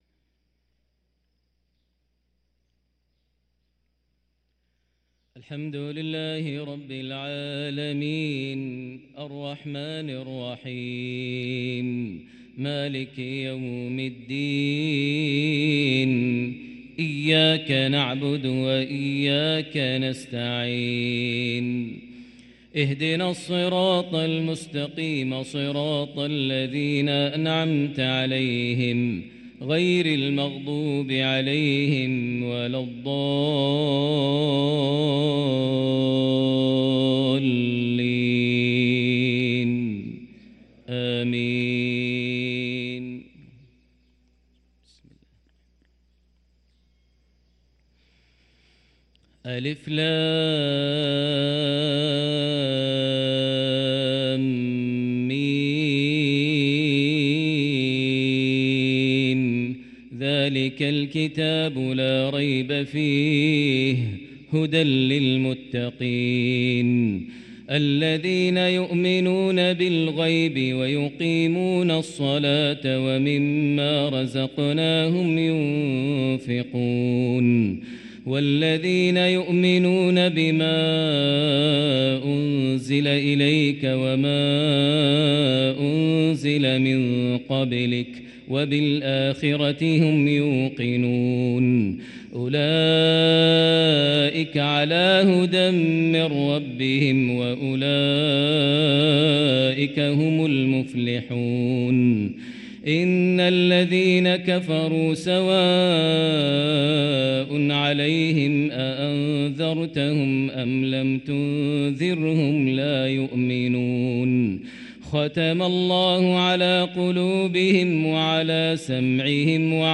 Fajr prayer from Surat Al-baqarah 9-3-2023 > 1444 H > Prayers - Maher Almuaiqly Recitations